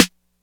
WTRY_SNR.wav